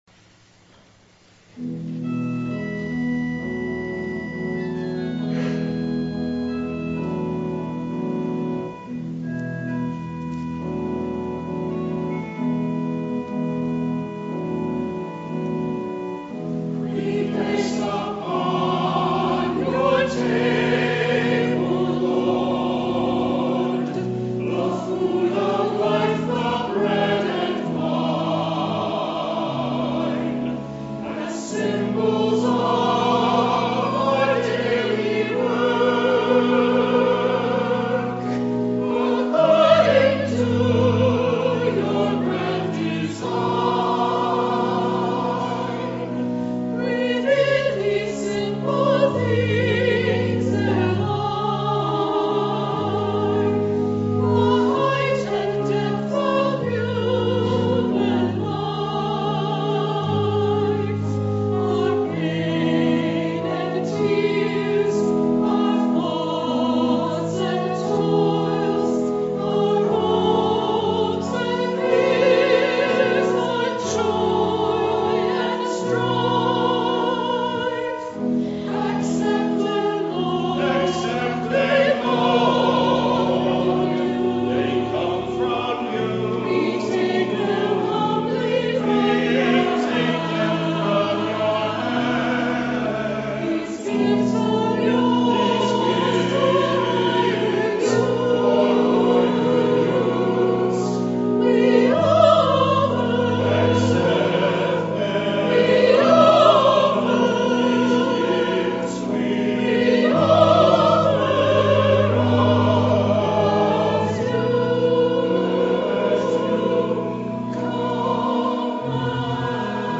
The Second Reformed Chancel Choir sings "Communion Prayer" by Allen Pote
Anthems